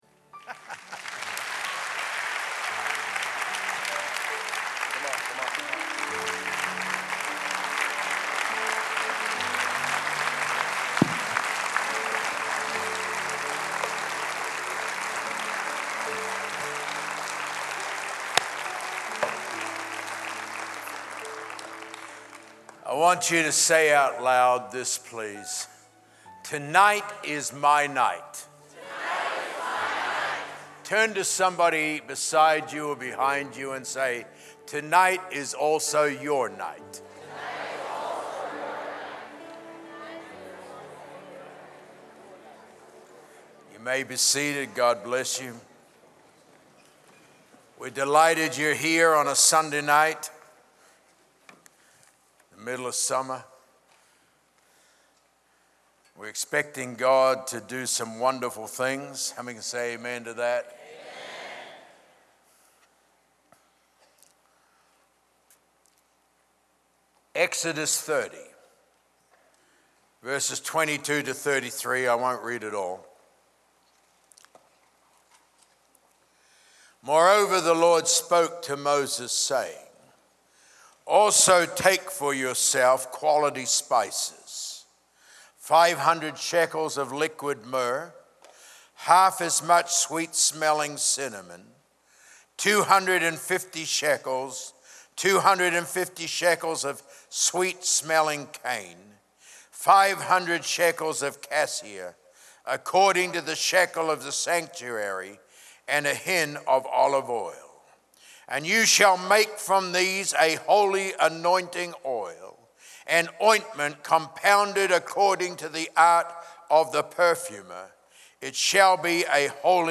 Healing Service | The Rock Church